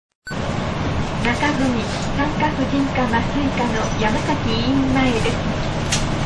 車内放送「
というふうに、一つのバス停で２回車内放送が用意されていた。
ってか、「山崎医院前です」のあたりで放送の声が苦しそうに感じる・・・（謎）